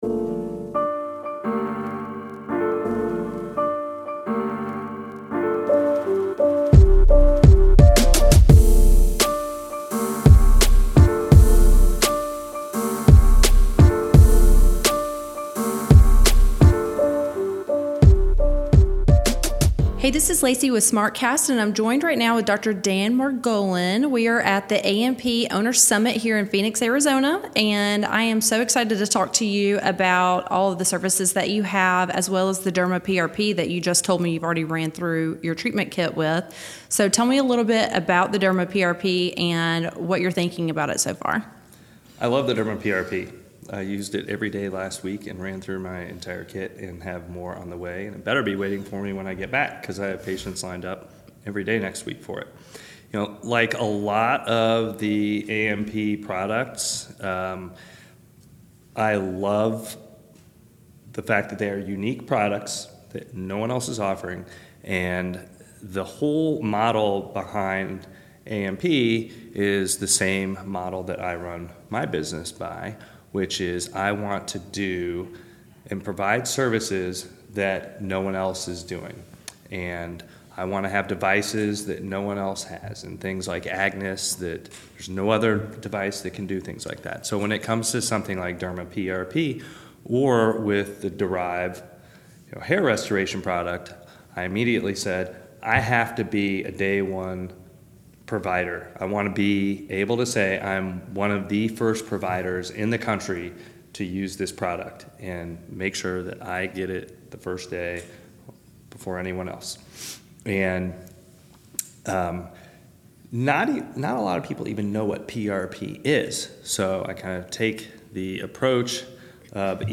In a captivating interview